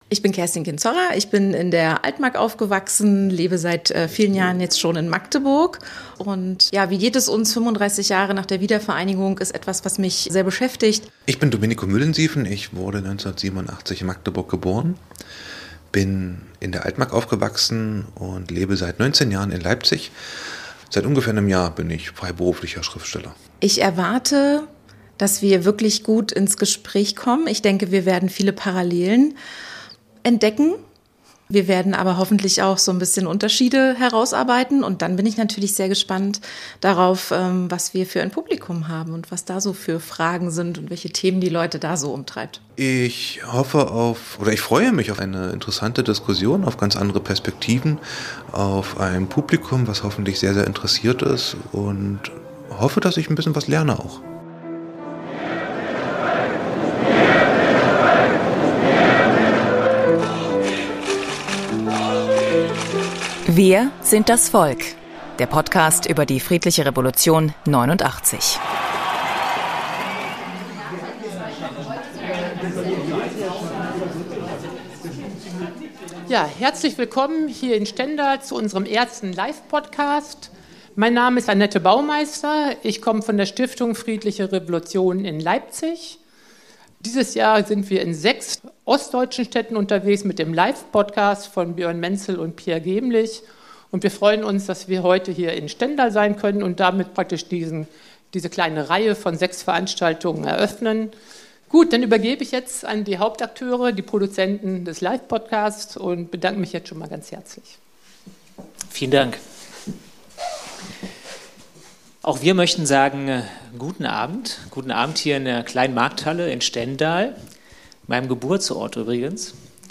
Über diese Szene und Frage u.a. haben wir bei unserem ersten Live-Podcast gesprochen...
Wo? In Stendal.